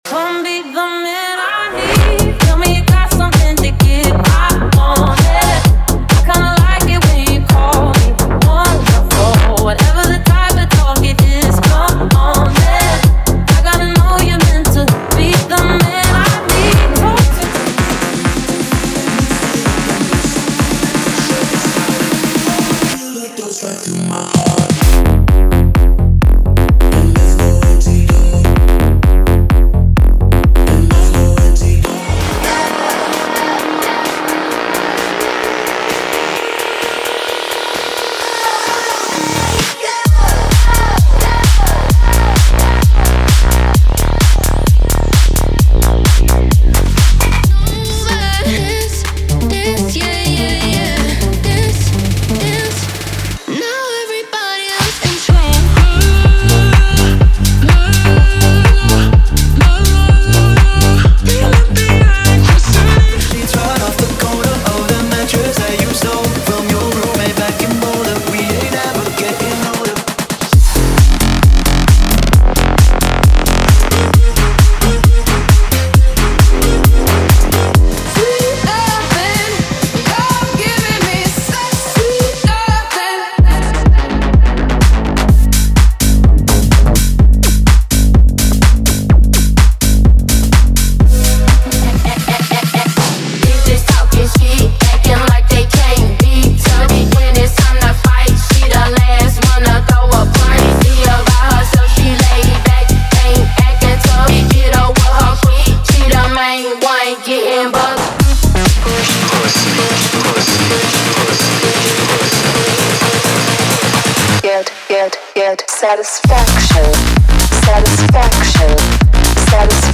Os Melhores Tech House do momento estão aqui!!!
• Versões Extended
• Sem Vinhetas